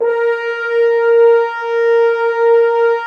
Index of /90_sSampleCDs/Roland L-CD702/VOL-2/BRS_F.Horns 2 f/BRS_FHns Dry f